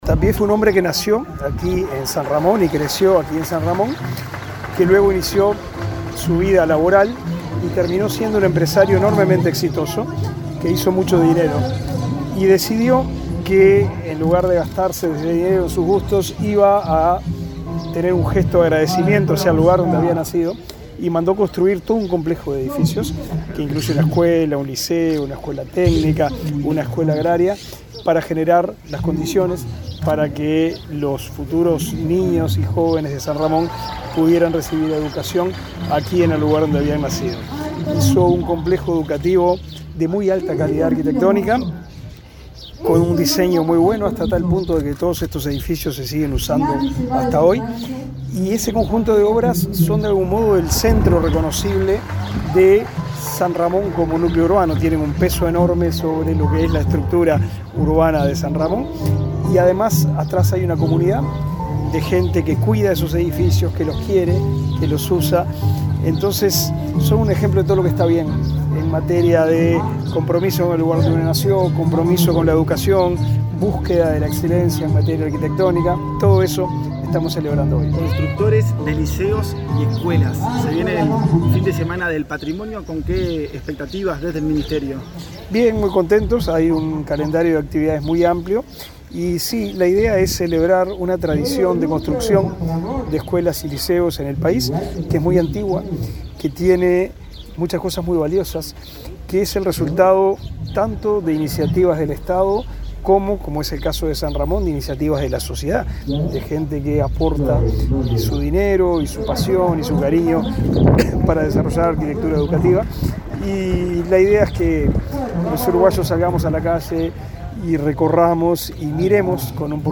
Declaraciones del ministro de Educación y Cultura, Pablo da Silveira
Declaraciones del ministro de Educación y Cultura, Pablo da Silveira 28/09/2023 Compartir Facebook X Copiar enlace WhatsApp LinkedIn El ministro de Educación y Cultura, Pablo da Silveira, fue entrevistado para medios periodísticos en Canelones, luego de participar, en el complejo educativo Tapié -Piñeyro de San Ramón, del acto del lanzamiento del Día del Patrimonio 2023.